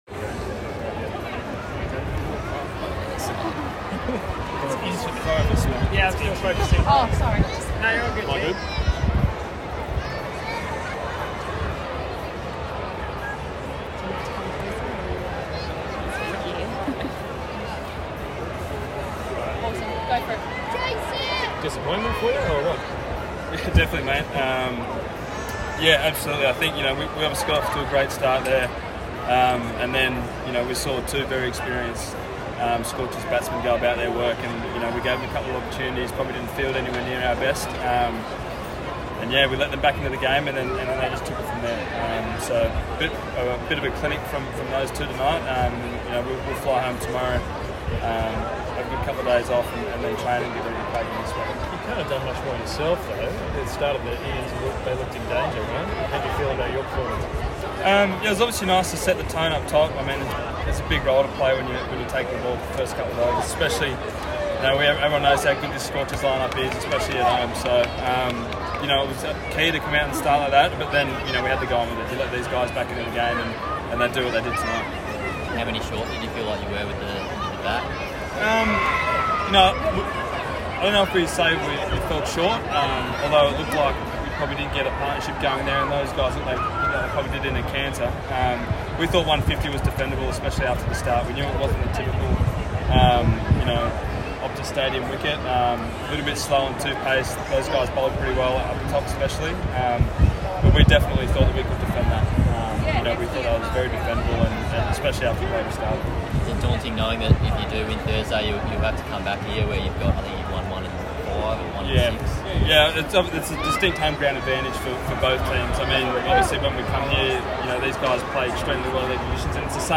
Sean Abbott (3-25) speaking post Sixers loss to the Scorchers in the Qualifer Final tonight at Optus Stadium.